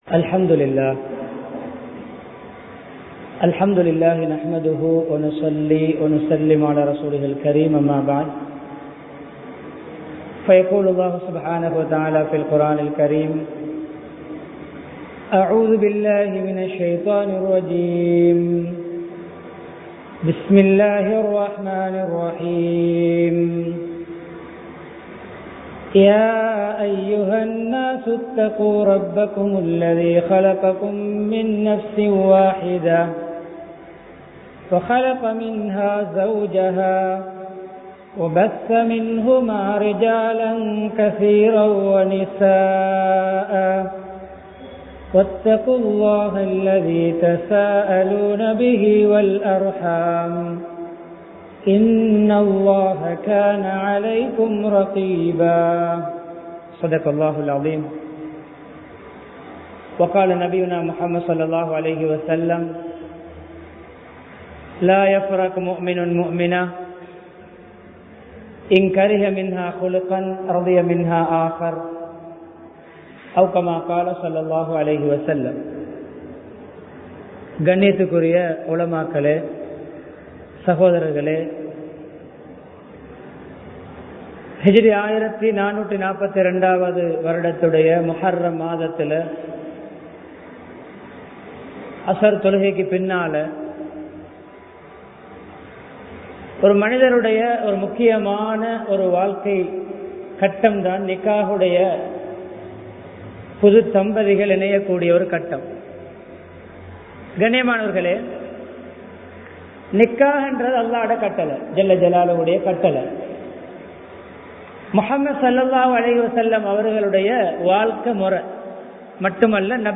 அன்பான குடும்ப வாழ்க்கை (Lovable Family Life) | Audio Bayans | All Ceylon Muslim Youth Community | Addalaichenai
Town Jumua Masjidh